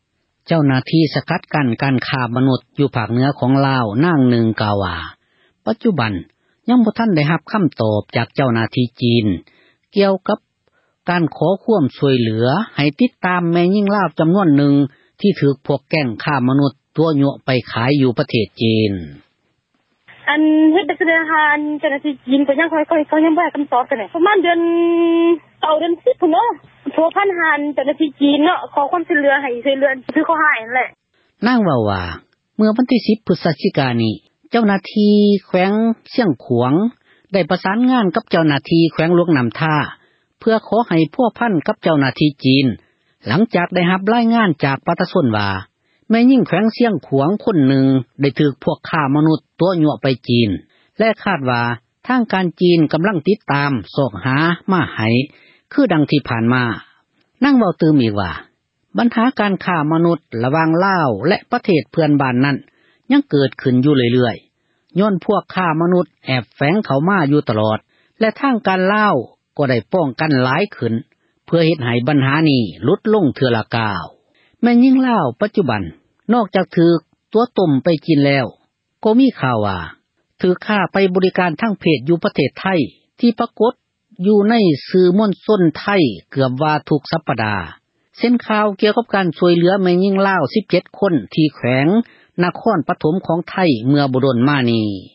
ເຈົ້າໜ້າທີ່ ປາບປາມ ການ ຄ້າມະນຸດ ຢູ່ ພາກເໜືອ ຂອງລາວ ນາງນຶ່ງ ກ່າວວ່າ ປັດຈຸບັນ ຍັງບໍ່ມີ ຄໍາຕອບ ຈາກ ເຈົ້າໜ້າທີ່ ຈີນ ກ່ຽວ ກັບ ການ ຂໍຄວາມ ຊ່ວຍເຫຼືອ ໃຫ້ຕິດ ຕາມ ແມ່ຍິງລາວ ຄົນນຶ່ງ ທີ່ ຖືກພວກ ຄ້າມະນຸດ ຕົວະໄປຂາຍ ຢູ່ ປະເທສຈີນ: